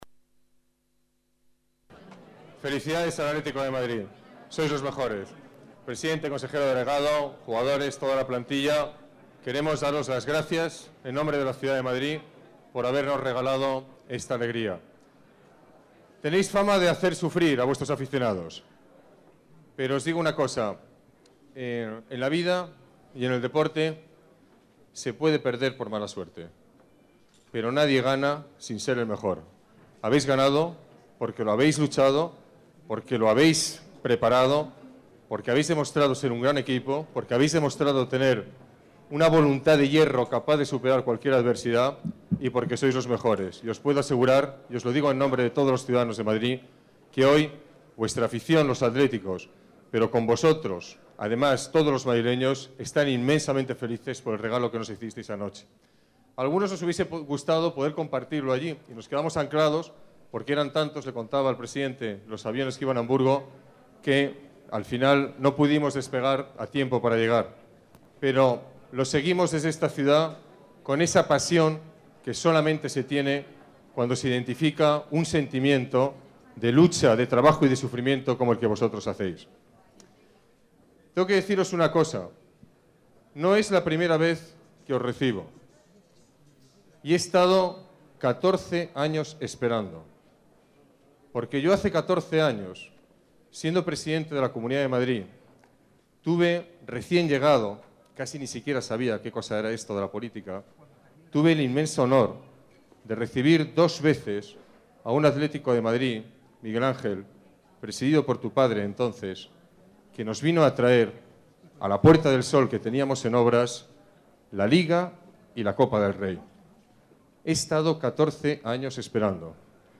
Nueva ventana:Ruiz-Gallardón, alcalde de la Ciudad de Madrid, felicita al club rojiblanco
GallardonRecibimientoAtleticoMadrid-13-05.mp3